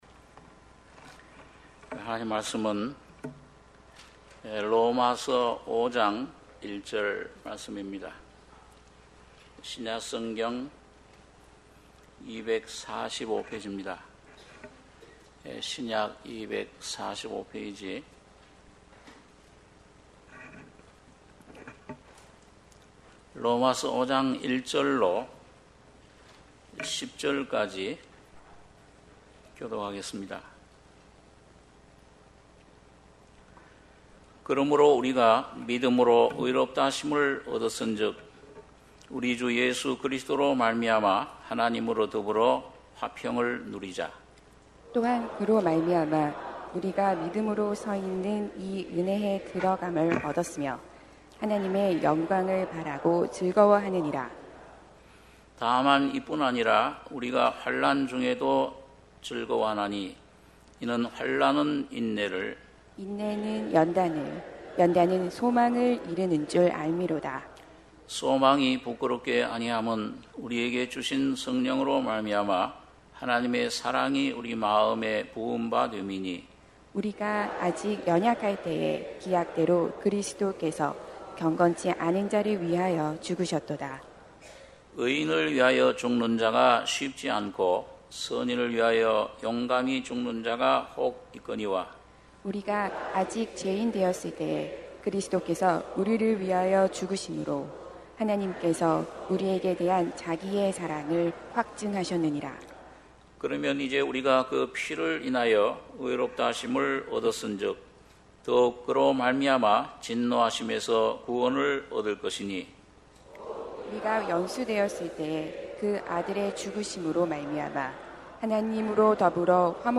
주일예배 - 로마서 5장 1절~10절 주일1부